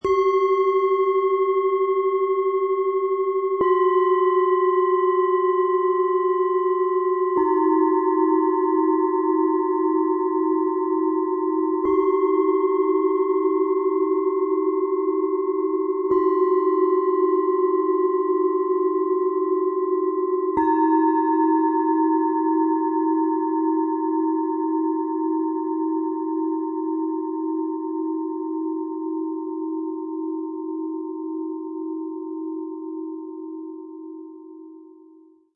Im Sound-Player - Jetzt reinhören kannst du den Original-Klang genau dieser Schalen live erleben – und direkt spüren, wie sie wirken.
Ein gratis Klöppel ist bereits enthalten – damit die Schalen warm und voll erklingen.
Diese drei Schalen lassen das Set harmonisch schwingen:
Tiefster Ton: Alphawelle
Bihar-Schale, matt
Mittlerer Ton: Mond
Höchster Ton: Jupiter